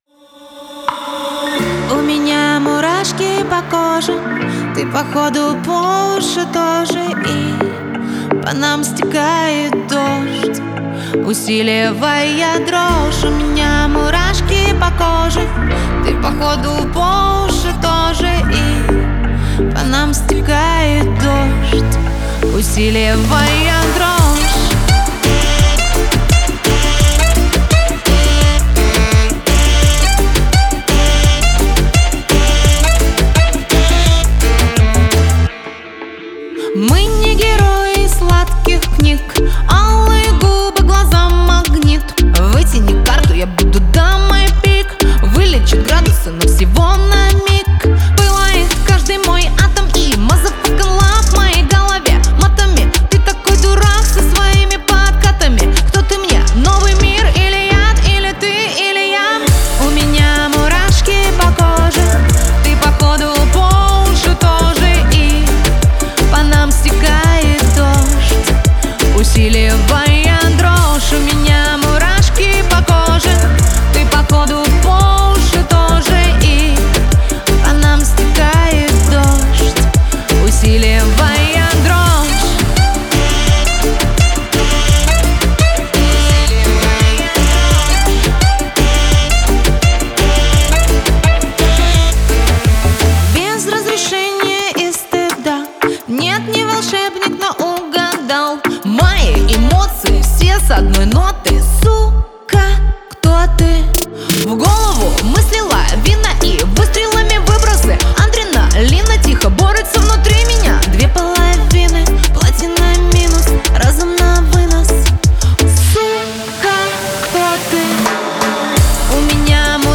Нашёл у себя демку с SM7, там правда с рулёжкой особо не заморачивался, т.к. нужна было показать всего лишь как вокал собрался. Он должен был вставляться в мультитрек плейбека для лайвов на случай больного горла и уличных мероприятий. Записано в Manley VoxBox через Prism AD-124.